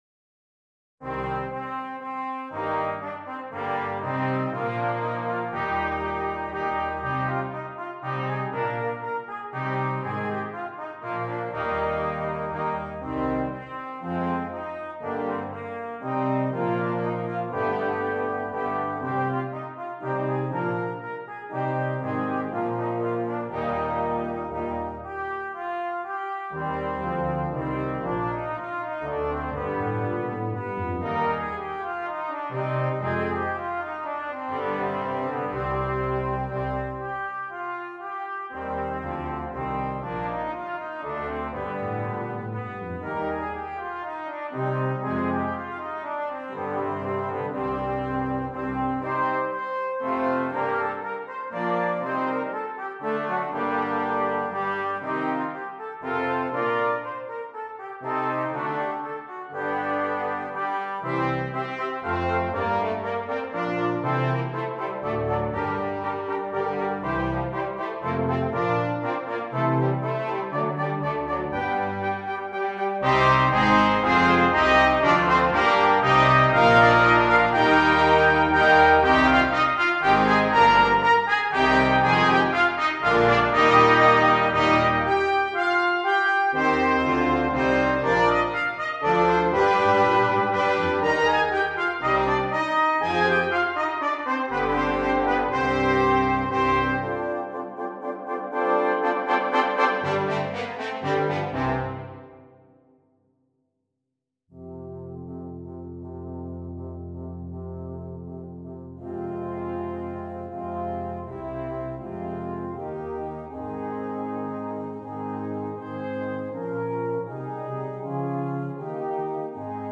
Double Brass Quintet